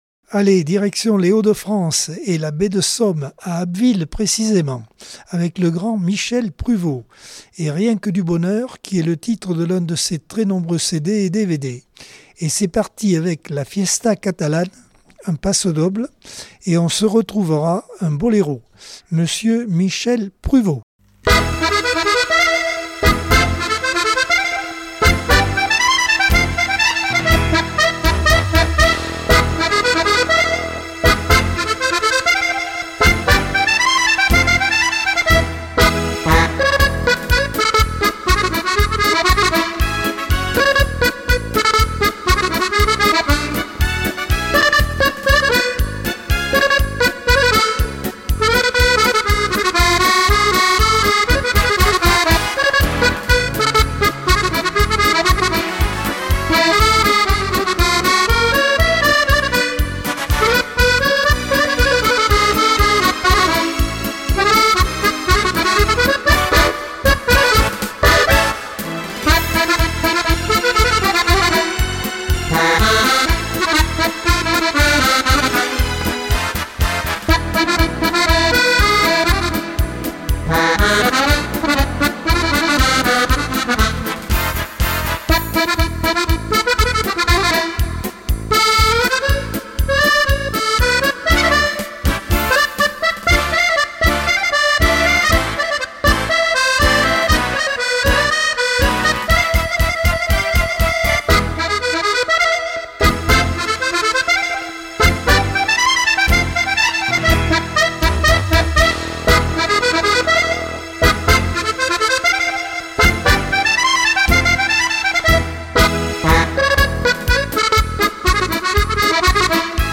Accordeon 2024 sem 47 bloc 2 - Radio ACX